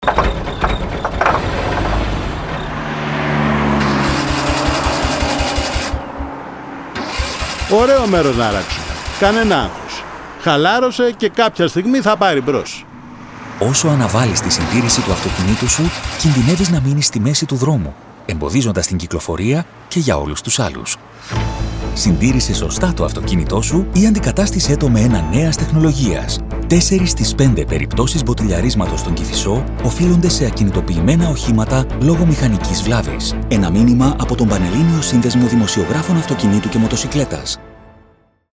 Ραδιοφωνικά μηνύματα σχετικά με τη δημόσια υγεία, την οδική ασφάλεια και την εξυπηρέτηση της κυκλοφορίας στα αστικά δίκτυα, μεταδίδονται από αρχές Ιουλίου (προς το παρόν σε περιορισμένο δίκτυο ραδιοφωνικών σταθμών του Λεκανοπεδίου).